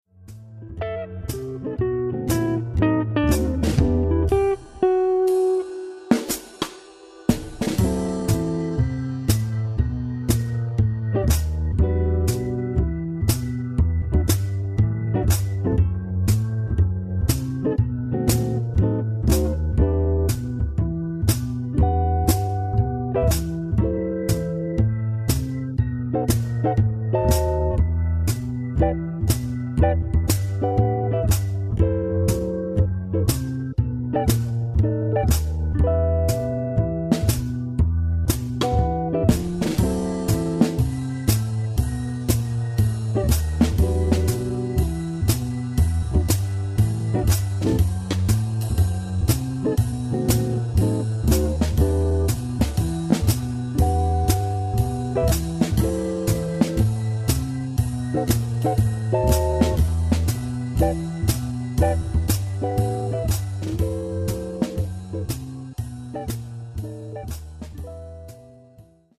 Aquí encuentras la base musical.
AHCOD - Audio_Em Backing Track.mp3